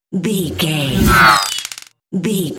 Sci fi appear whoosh
Sound Effects
Atonal
futuristic
high tech
whoosh